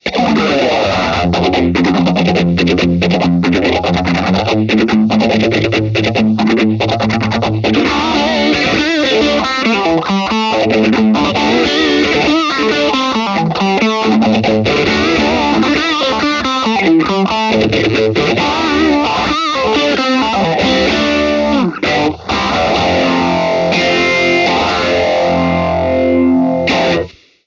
Vintage Phaser
Chitarra: Brian May Red Special
Gear: Rock You Treble Booster, Red Badger, Vox AC30
Mode: Custom (B)
Speed: 3/10